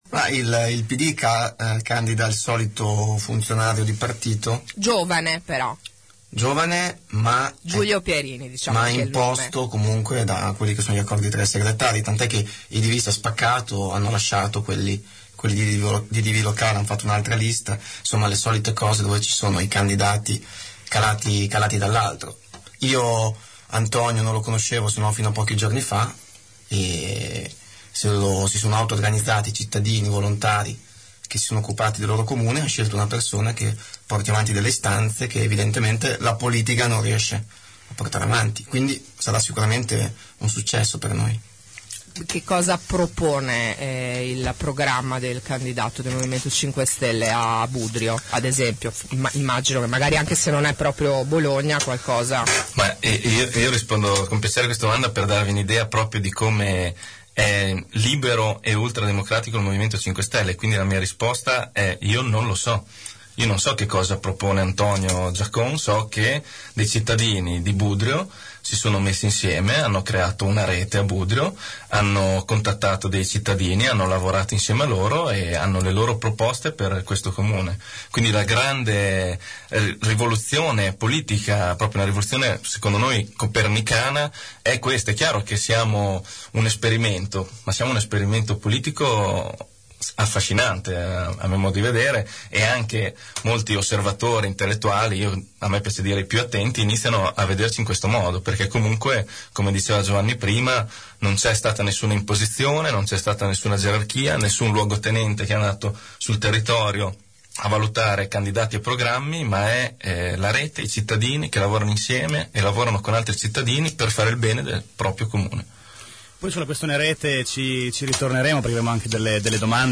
Giovanni Favia, consigliere regionale del Movimento 5Stelle, è stato nostro ospite assieme al capogruppo grillino in consiglio comunale Massimo Bugani.